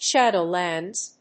/ˈʃædoˌlændz(米国英語), ˈʃædəʊˌlændz(英国英語)/